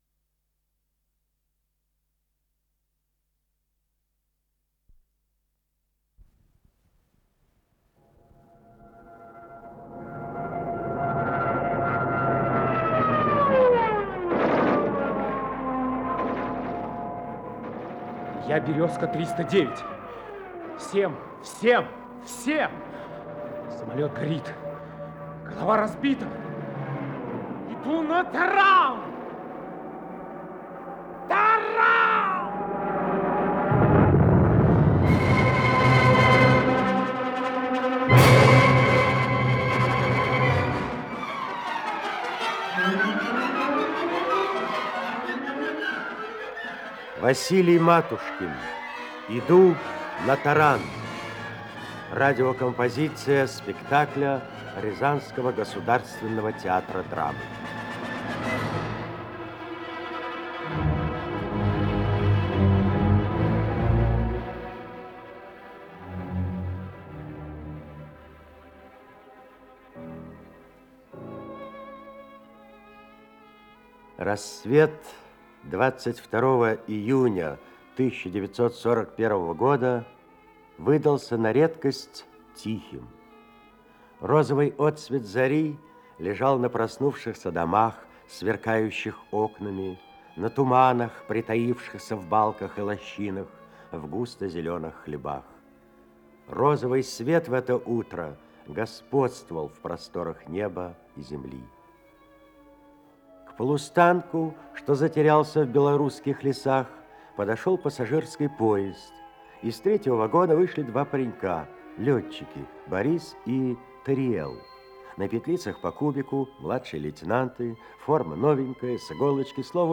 Исполнитель: Артисты Рязанского Драматического театра
Композиция спектакля Рязанского Драматического театра